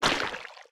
Sfx_creature_spikeytrap_attack_exit_01.ogg